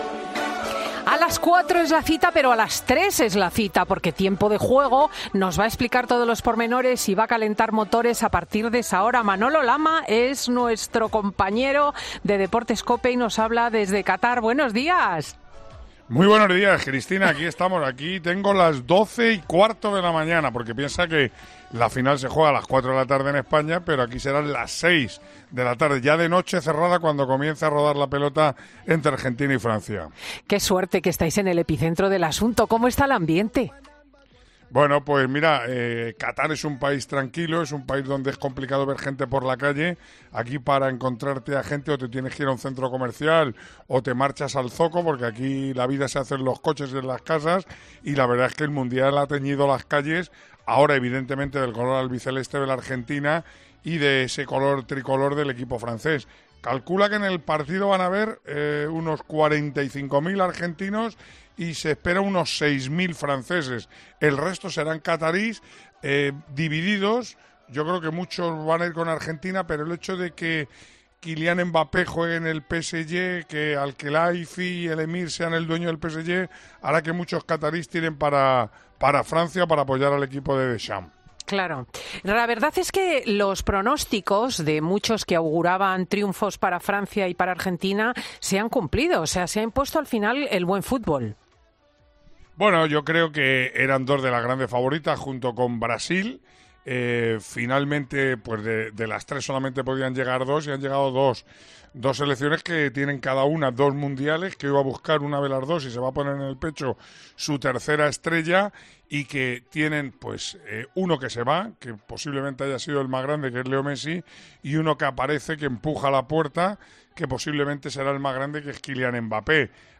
El periodista y narrador de 'Tiempo de Juego' ha pasado por los micrófonos de 'Fin de Semana' desde Qatar para contarnos la última hora de la final del...